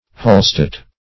hallstatt - definition of hallstatt - synonyms, pronunciation, spelling from Free Dictionary
Hallstatt \Hall"statt\ (h[aum]l"st[aum]t; -sht[aum]t),